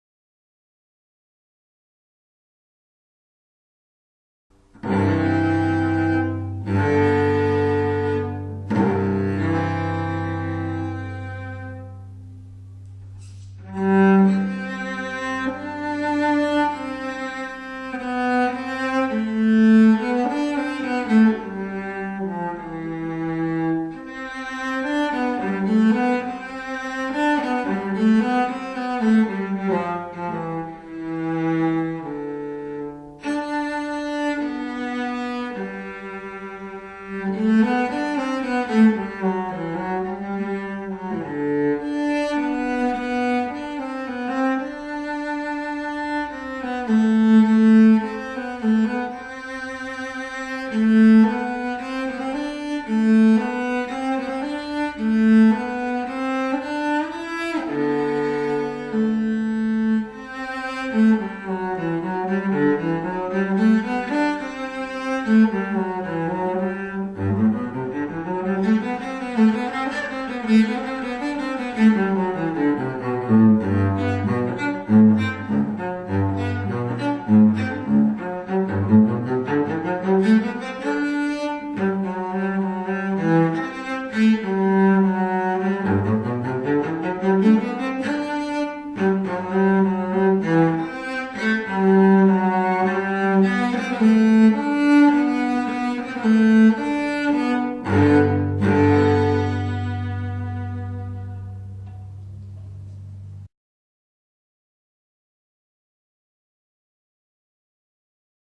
LAuihqlTfpq_Como-suena-un-cello-256kbps-cbr-.mp3